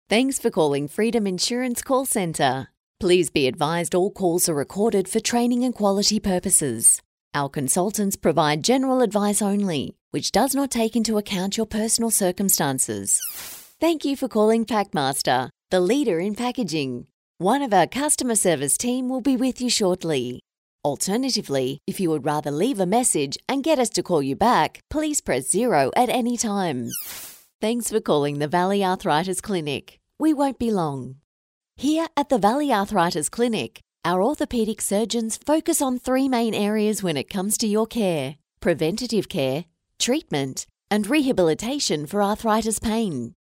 I’m an authentic Australian female voiceover artist with over 18 years of experience.
I can provide a quality studio recording from my home studio with a Rode NT1-A microphone and Adobe Audition editing software.
• On Hold